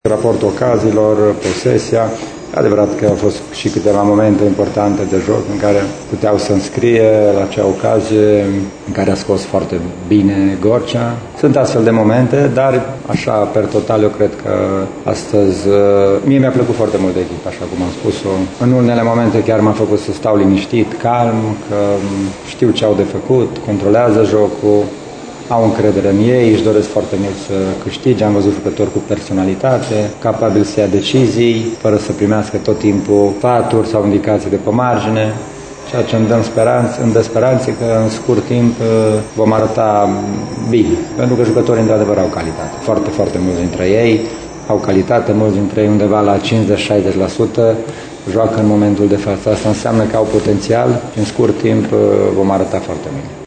Antrenorul oaspeților, Ioan Ovidiu Sabău, s-a declarat foarte mulțumit de evoluția elevilor săi, după o prestație care l-a „liniștit” în multe dintre momente: